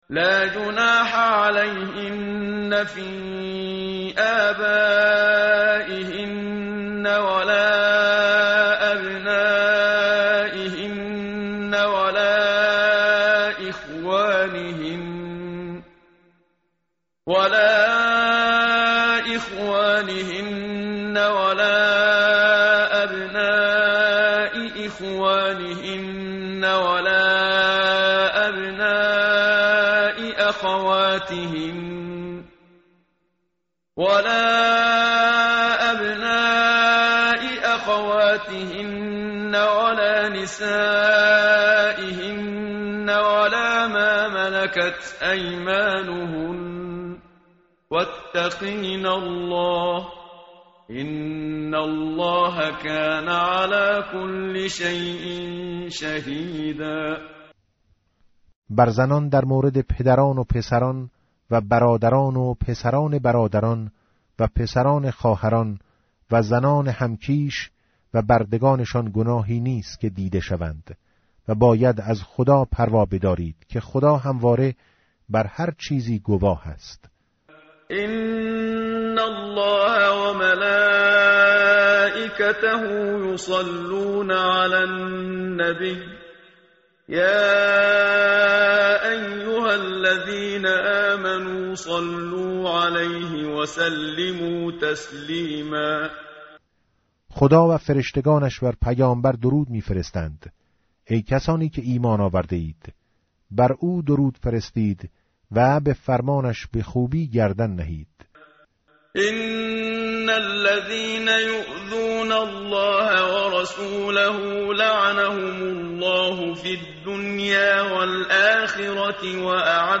متن قرآن همراه باتلاوت قرآن و ترجمه
tartil_menshavi va tarjome_Page_426.mp3